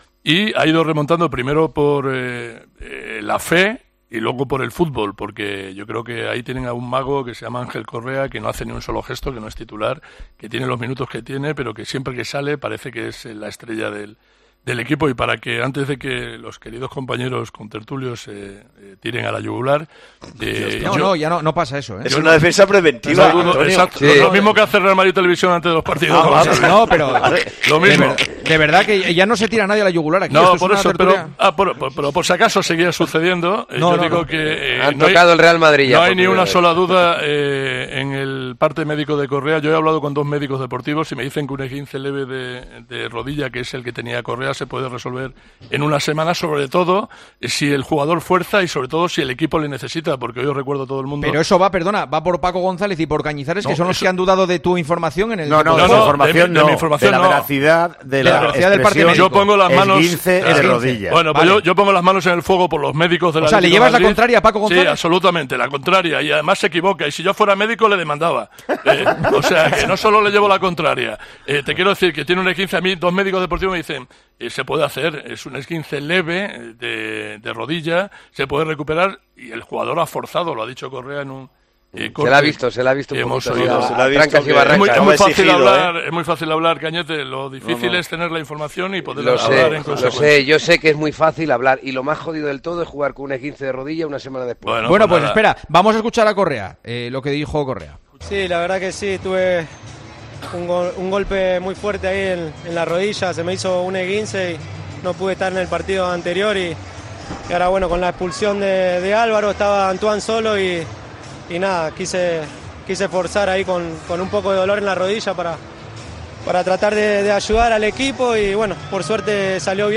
Tenso debate en El Tertulión sobre la lesión de Ángel Correa tras la entrada de Bellingham